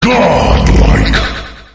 голосовые
из игр
эпичные